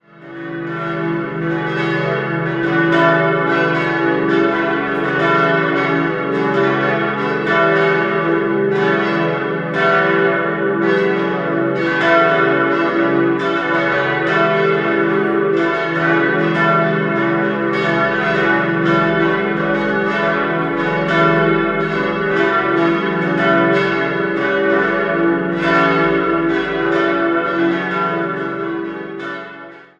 Den Chorraum dominiert ein zehn Meter hoher Wandteppich aus dem Jahr 1962 der Meersburger Künstlerin Edith Müller-Ortlof. 5-stimmiges Geläute: cis'-e'-fis'-gis'-h' Alle Glocken wurden von der Gießerei Otto in Bremen-Hemelingen gegossen, die kleine bereits 1936, die vier anderen 1957.